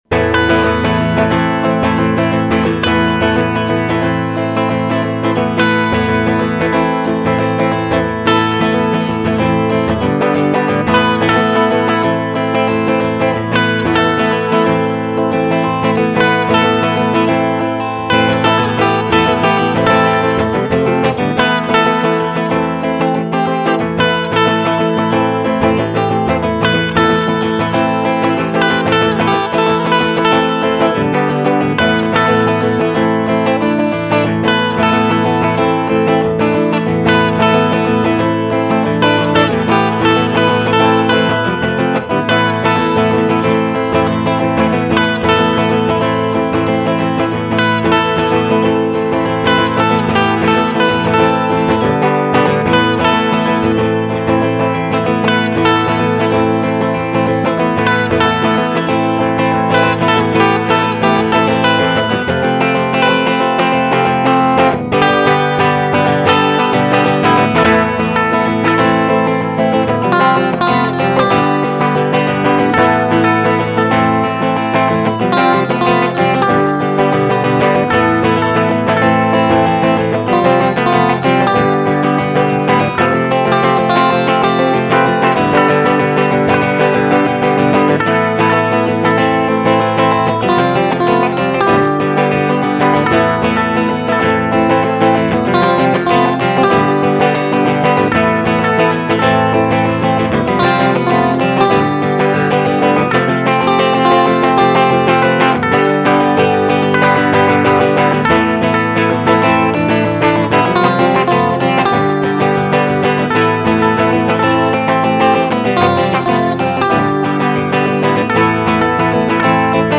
Genre: Freie Musik - Rock